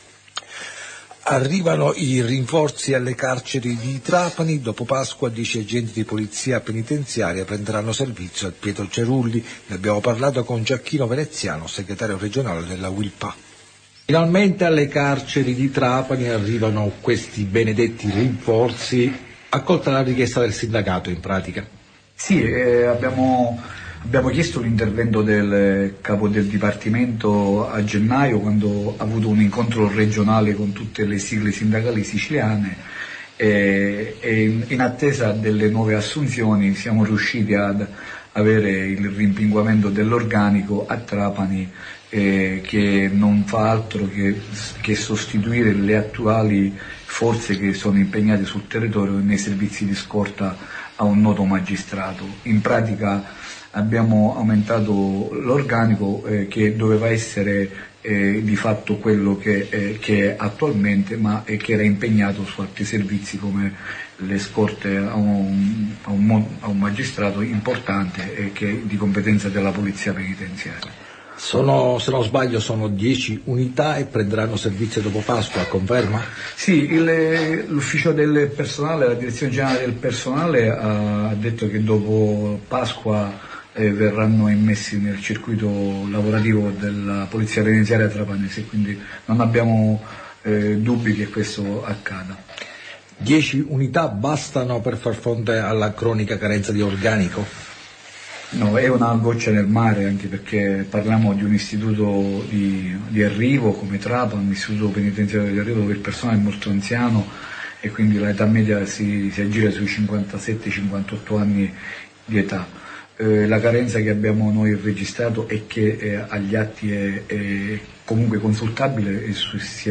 RINFORZI AL CARCERE DI TRAPANI E CAMPAGNA VACCINALE..INTERVISTA